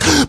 VEC2 Beatbox Mixed